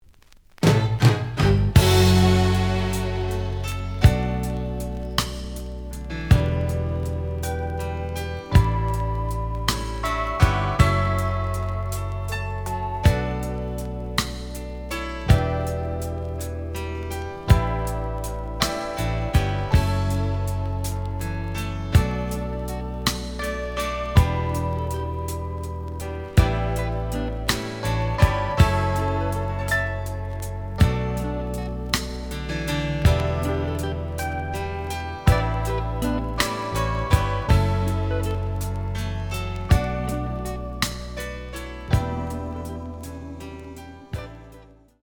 The audio sample is recorded from the actual item.
●Genre: Soul, 80's / 90's Soul
Slight edge warp.